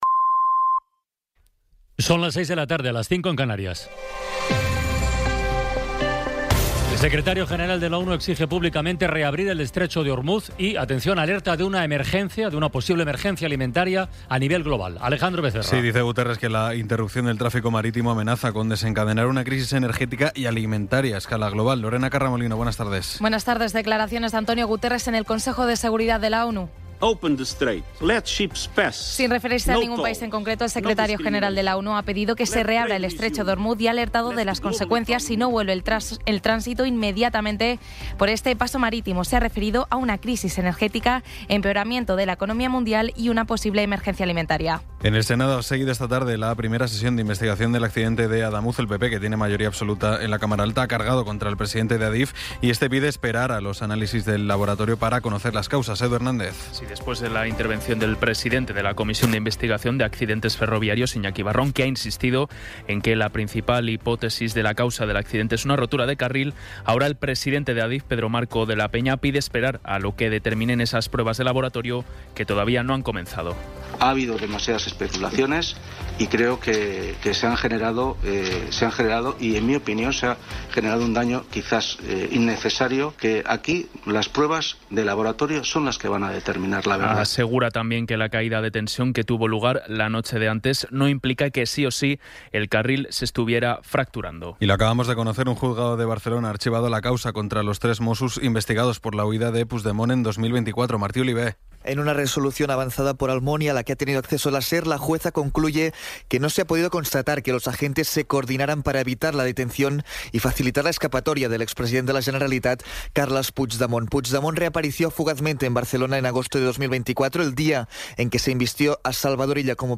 Resumen informativo con las noticias más destacadas del 27 de abril de 2026 a las seis de la tarde.